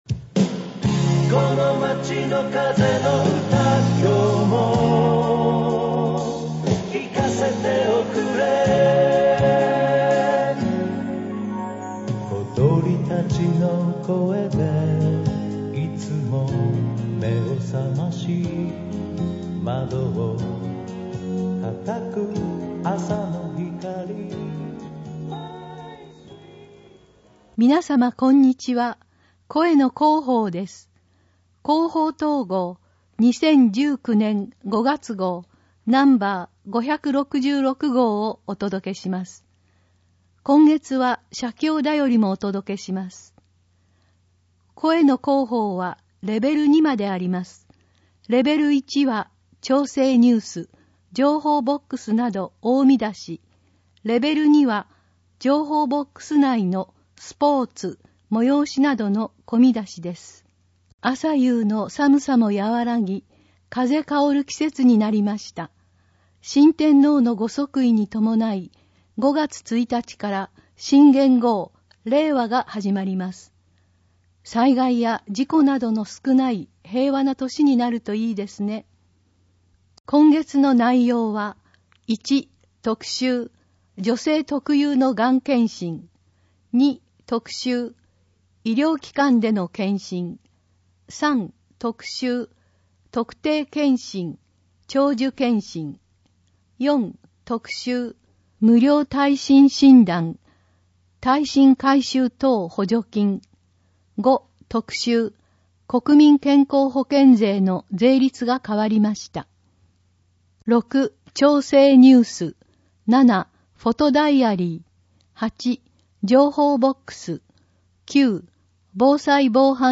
広報とうごう音訳版（2019年5月号）